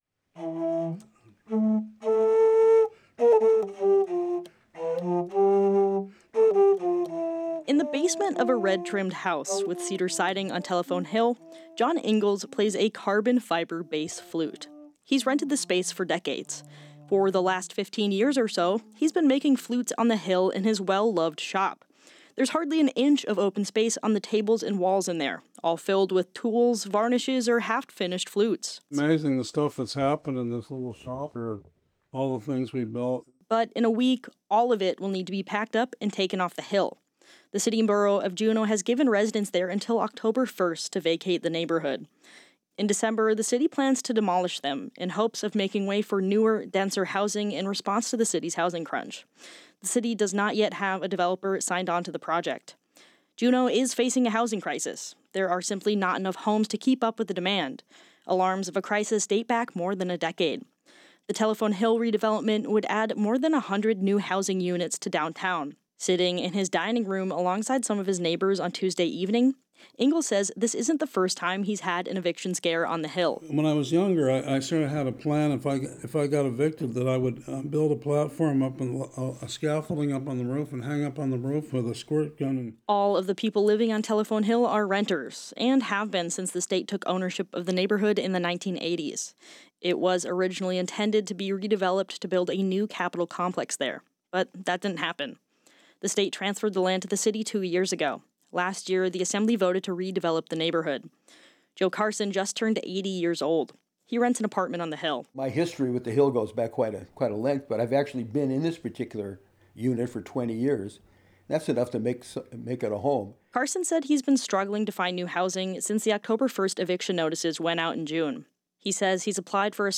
On Monday, dozens of residents attended the Juneau Assembly meeting to protest the city’s plans to evict residents.